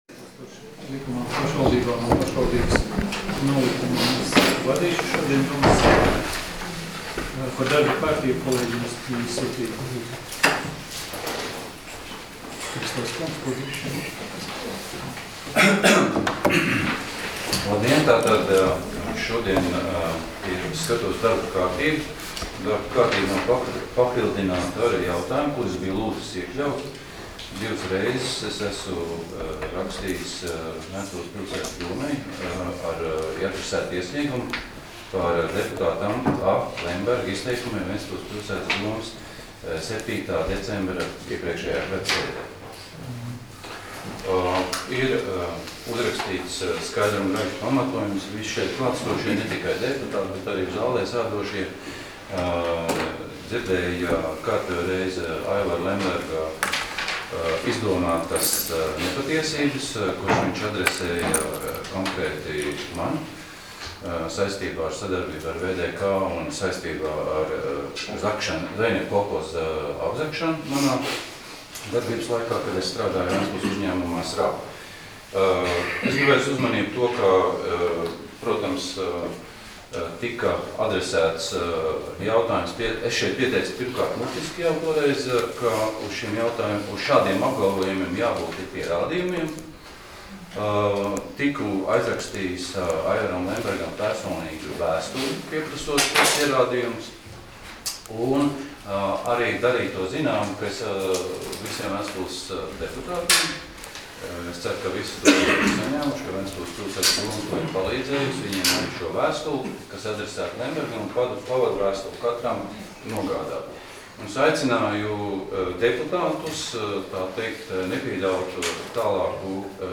Domes sēdes 25.01.2019. audioieraksts